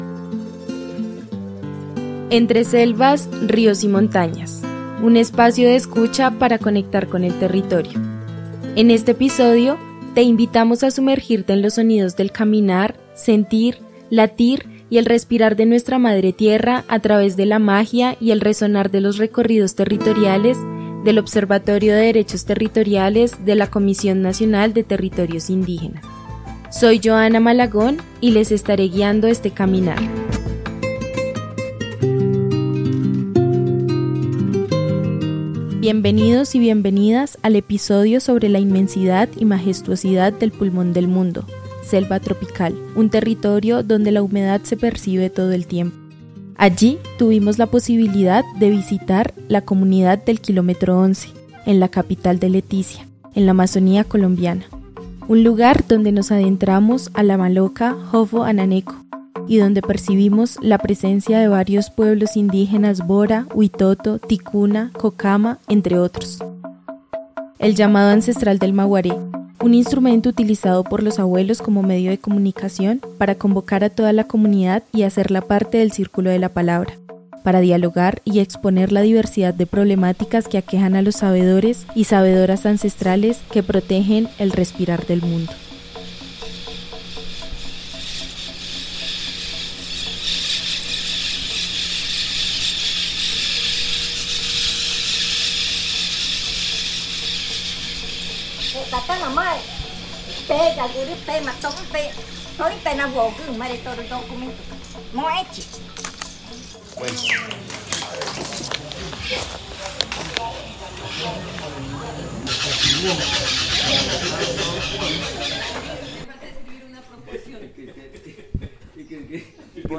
Paisaje Sonoro Leticia